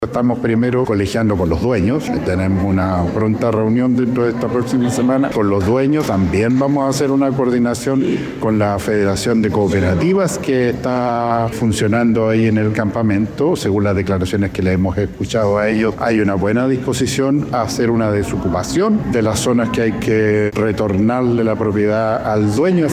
Según explicó el delegado presidencial Yanino Riquelme, el proceso se encuentra en una fase de diálogo y coordinación con los principales actores involucrados.